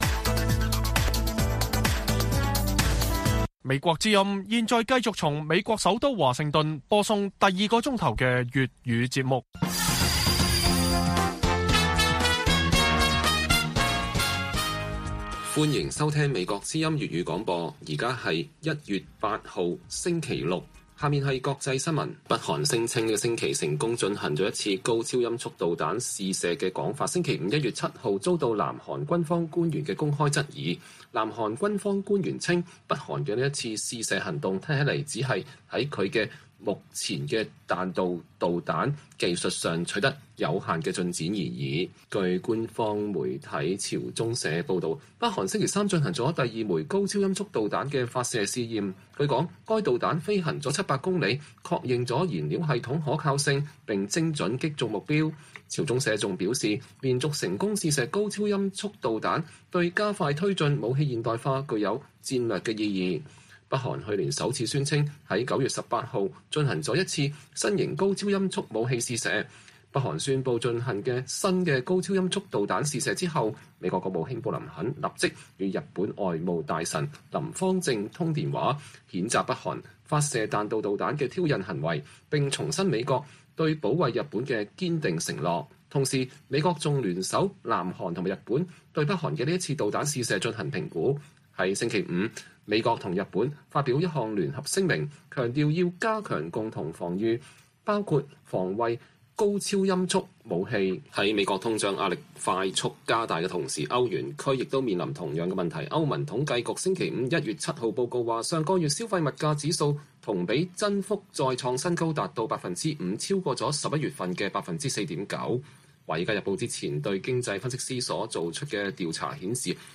粵語新聞 晚上10-11點 :中國打算擴充核武庫的一個原因